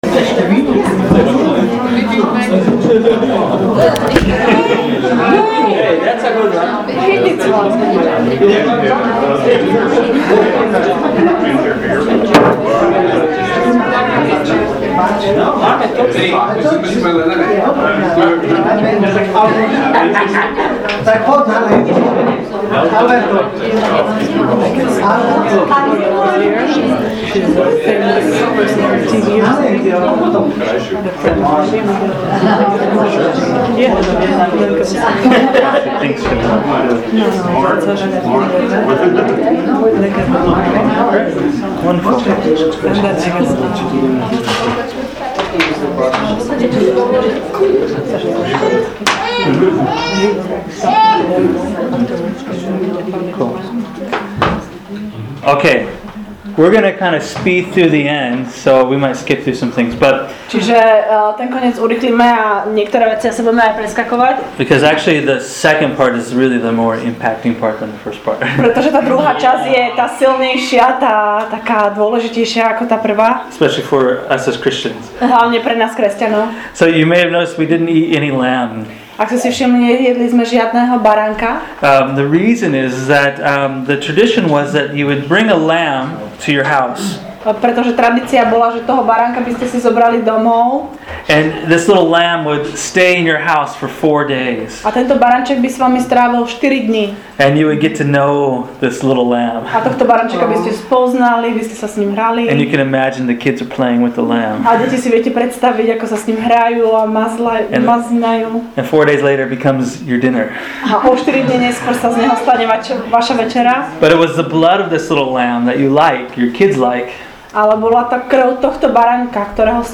Audio: 1st part of Service Then we ate! 2nd half of the Service Slides: PDF Event Pics: Passover 2011 Pictures Share this: Share on Facebook (Opens in new window) Facebook Share on X (Opens in new window) X Like Loading...
passover-2011-2nd-half.mp3